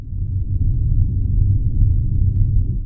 low hum.wav